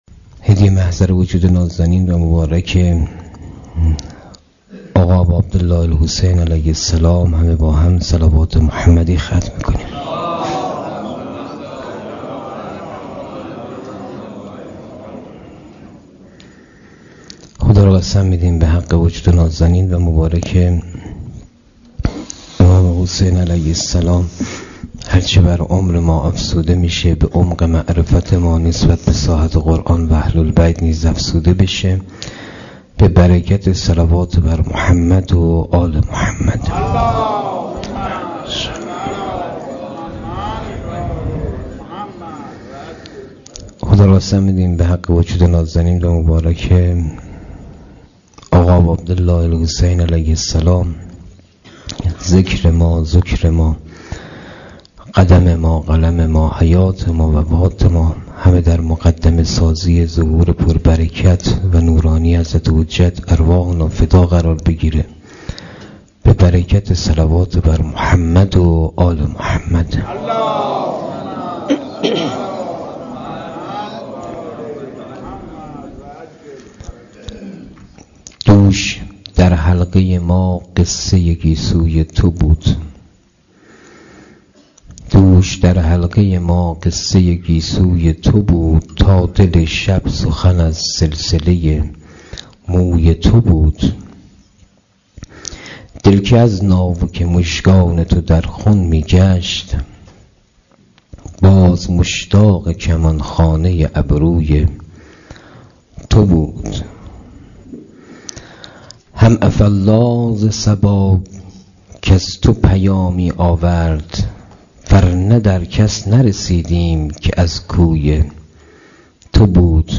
مسجد حضرت خدیجه - جلسه ششم